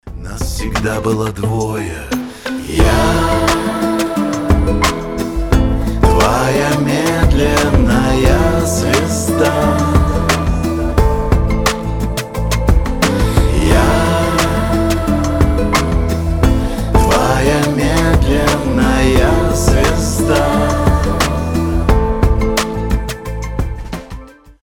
рок
дуэт , спокойные , медленные